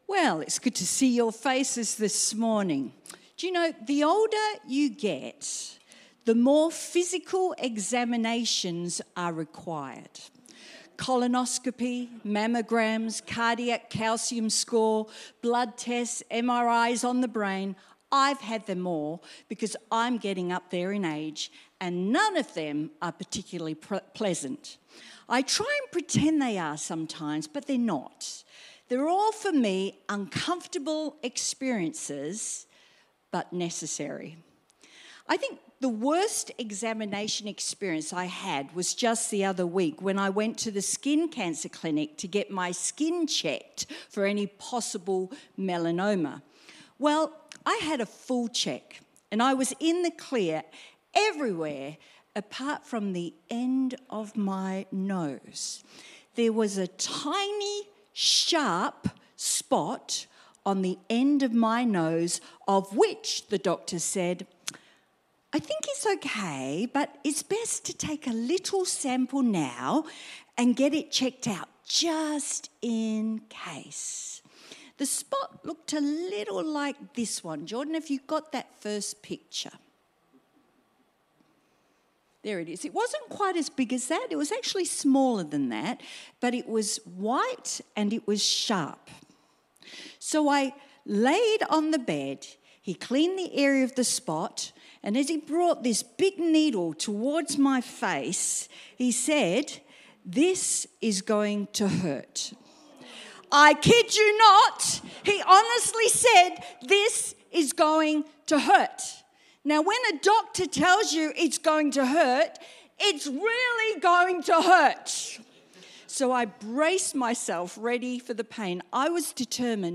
is a sermon